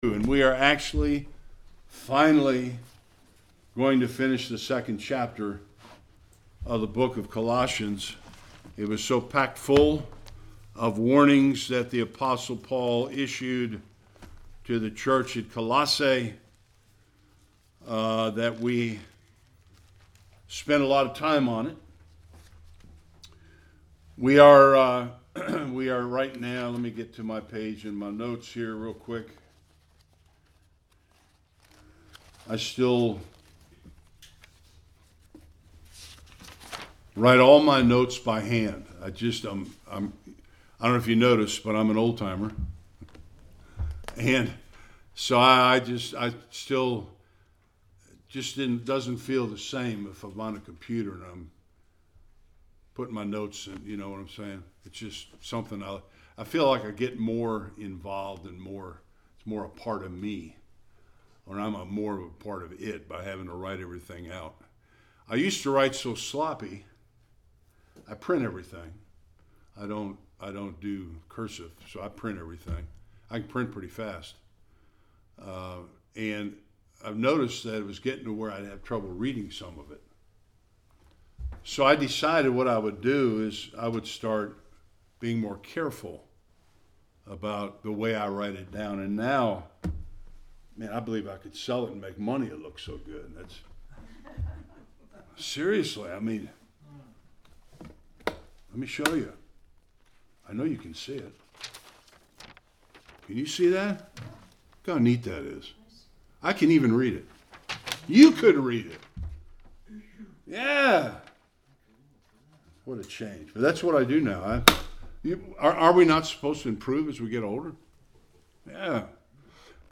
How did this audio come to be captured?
20-23 Service Type: Sunday Worship What is asceticism?